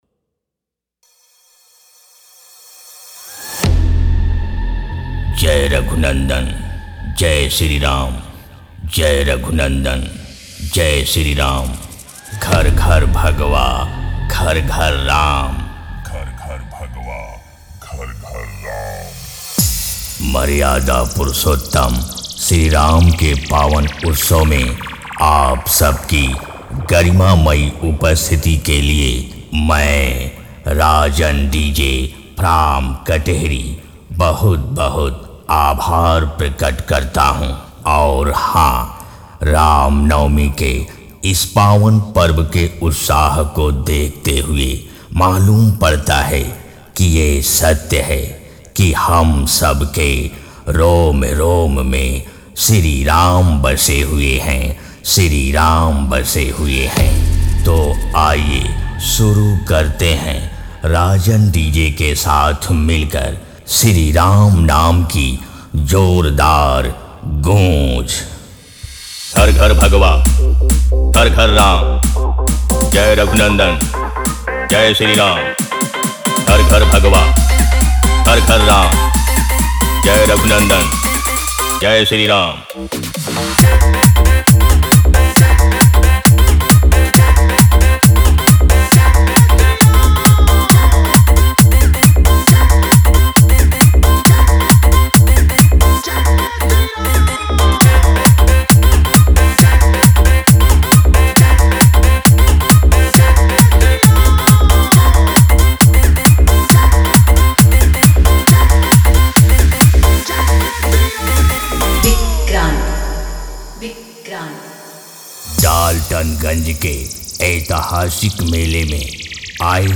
DJ Remix
Category : Sound Check Songs